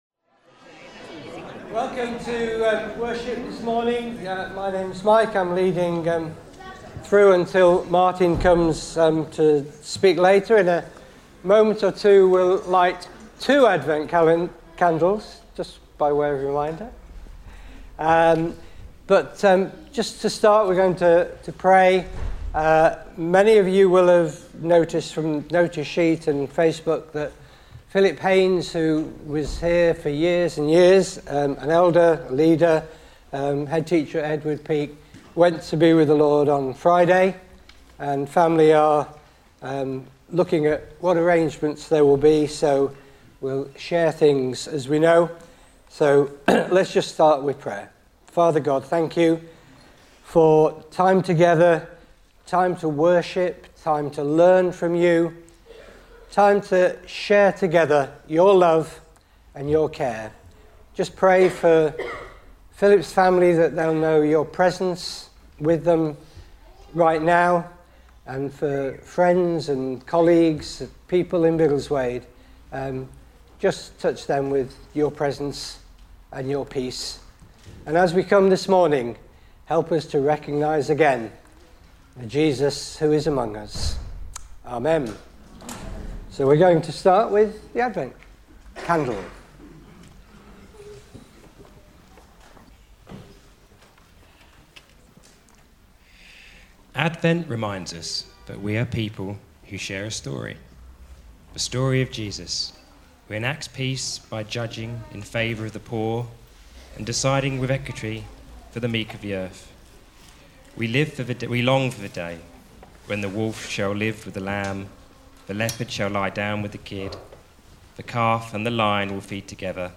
7 December 2025 – Morning Service